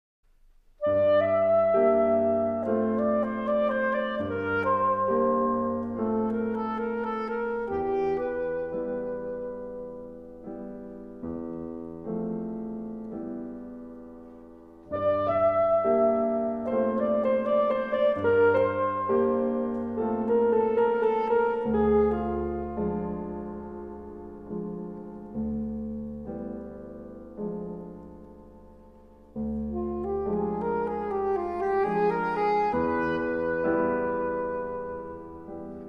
Arranging Symphonic, Vocal, and Piano Works for performance on Carillon
This translated to a contemplative musical style.
I left the melody in the middle.